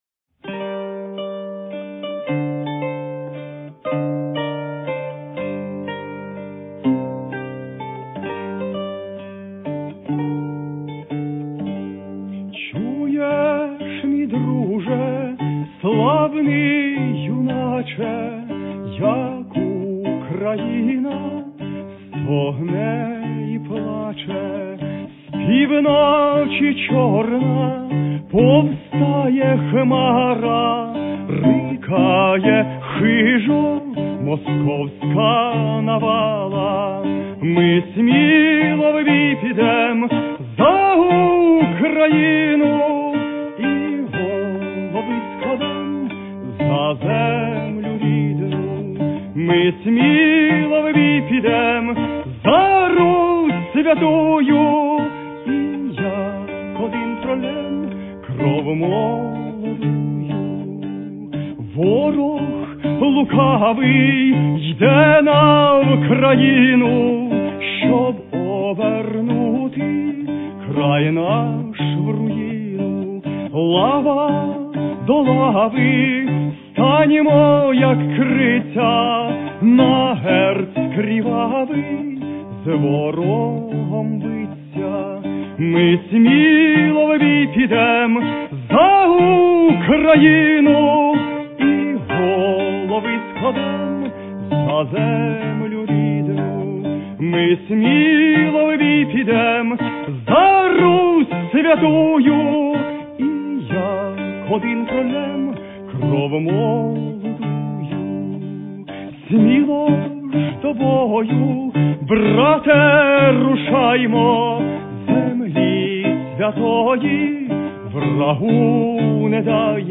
Військова музика та пісні